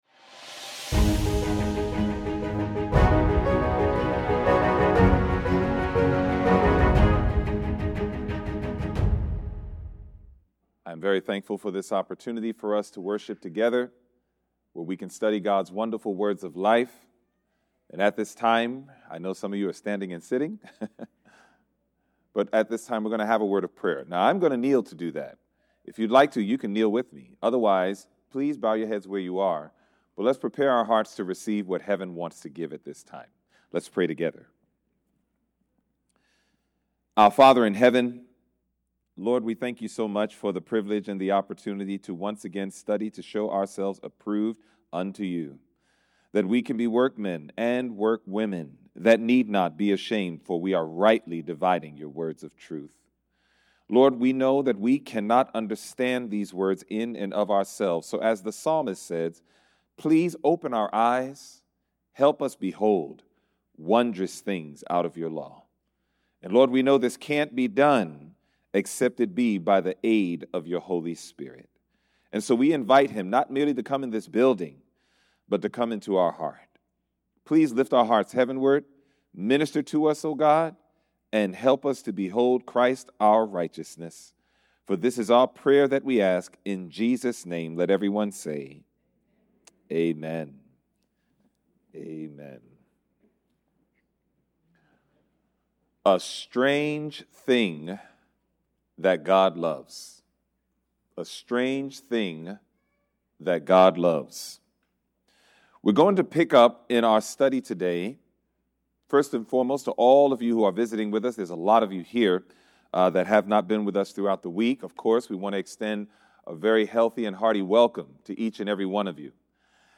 From Series: "Central Sermons"